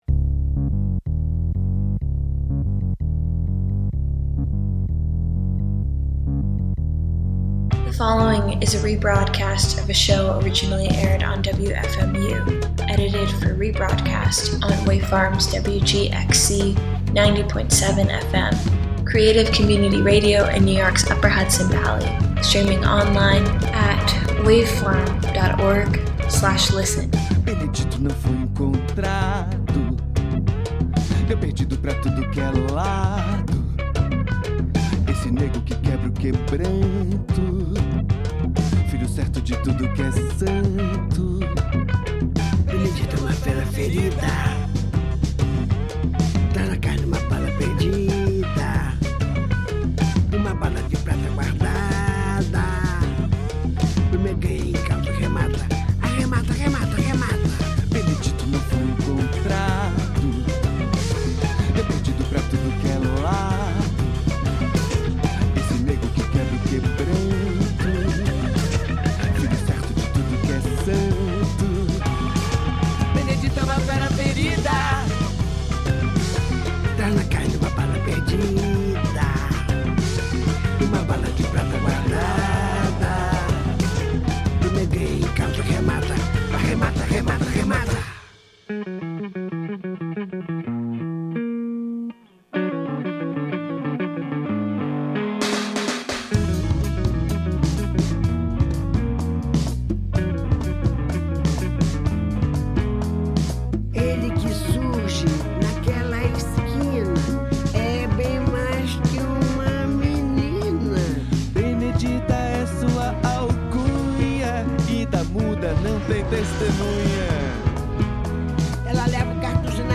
In a stew of intimate electronics, bad poetry, and tender murk, we swap tongues and reach for more.